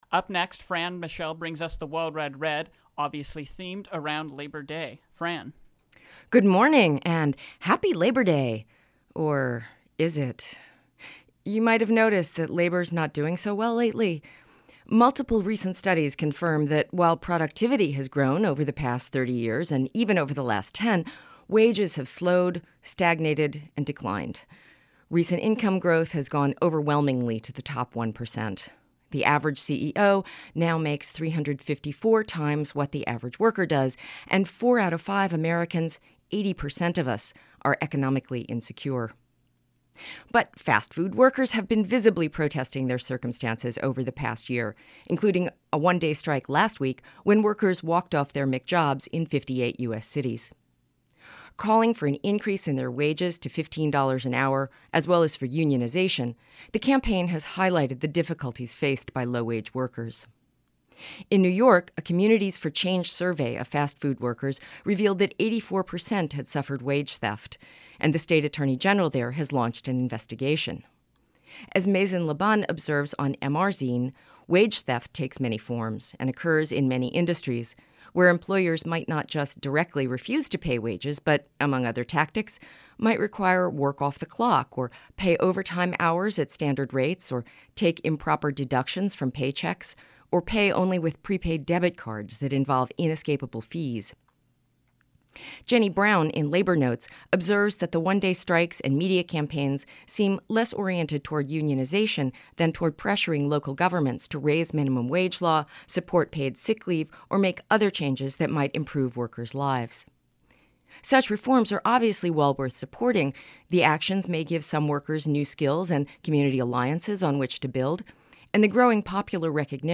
Commentary on labor and the wage-system as an economic injustice itself.